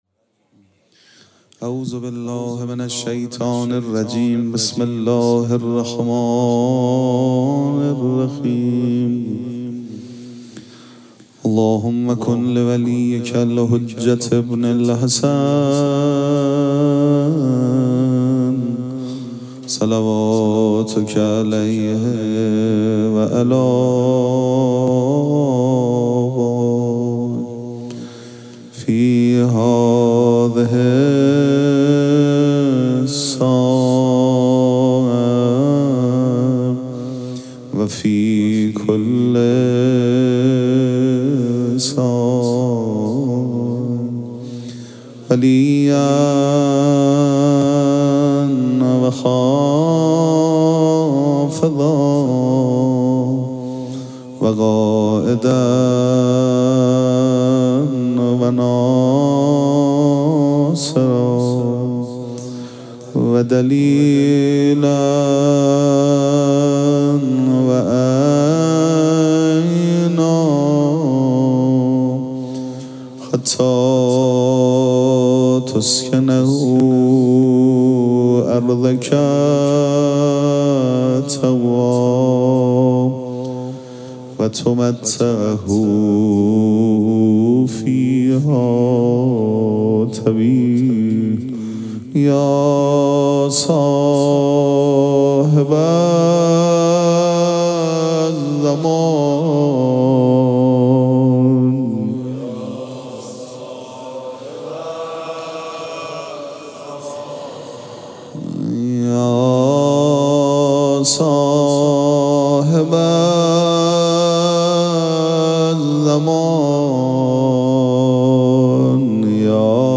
مراسم عزاداری شام غریبان محرم الحرام ۱۴۴۷